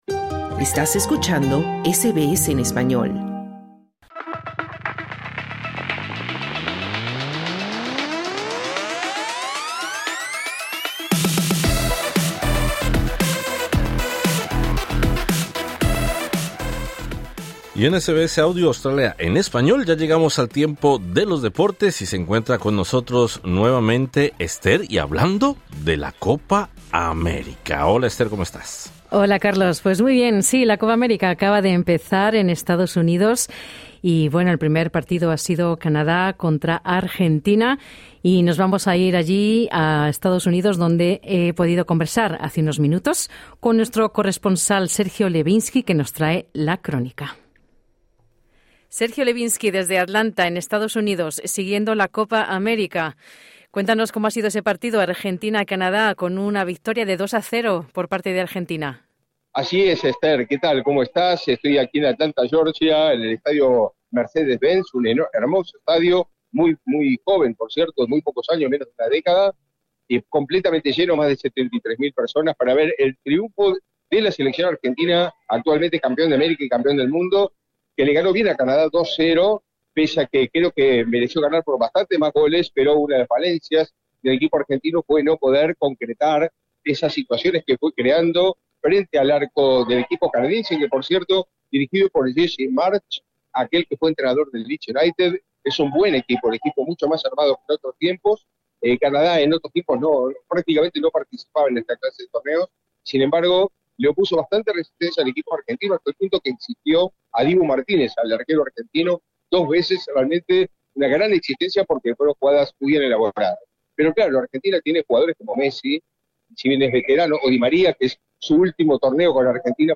Deportes SBS Spanish | 20 junio 2024